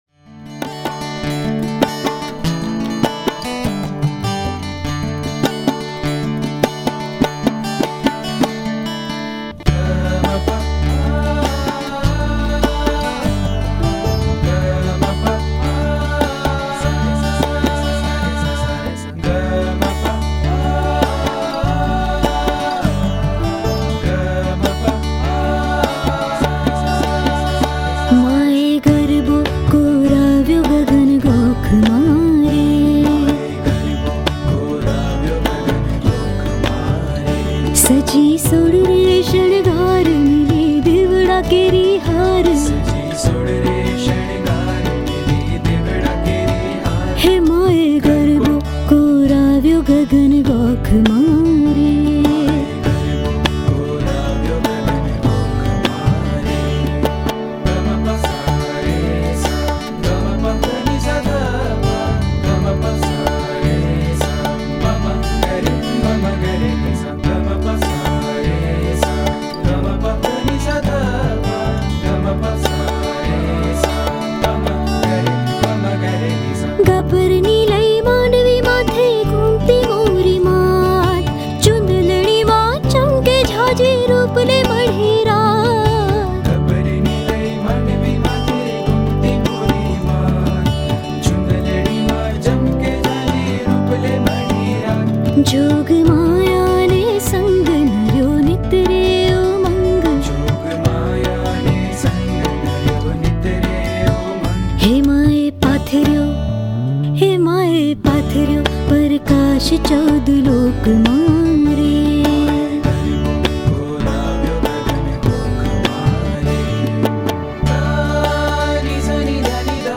ગીત સંગીત ગરબા - Garba
Unplugged